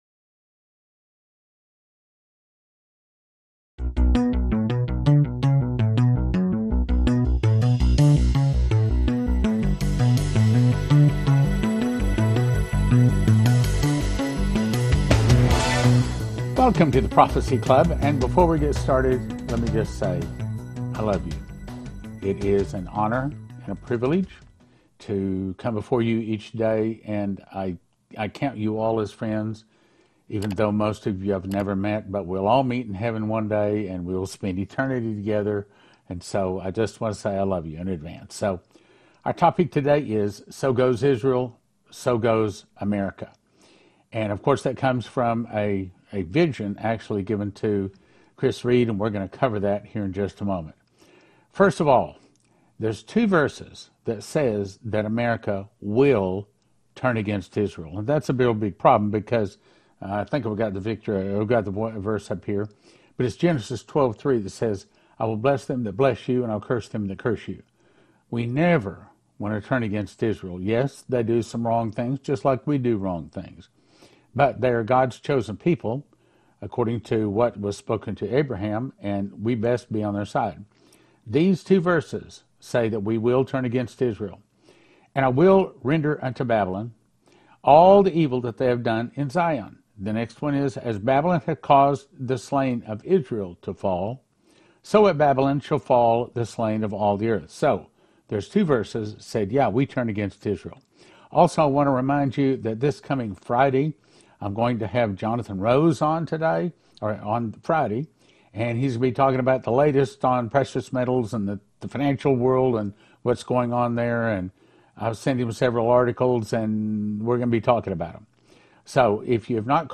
Talk Show Episode, Audio Podcast, The Prophecy Club and So Goes Israel So Goes America on , show guests , about So Goes Israel So Goes America, categorized as History,News,Philosophy,Politics & Government,Religion,Christianity,Society and Culture,Theory & Conspiracy